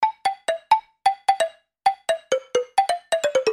• Качество: Хорошее
• Категория: Рингтон на смс